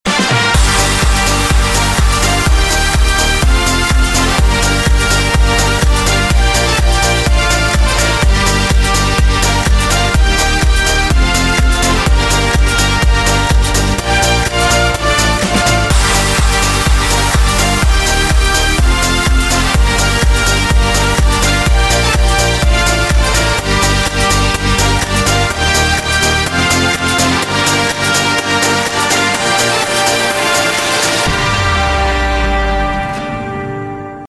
Kategorien Elektronische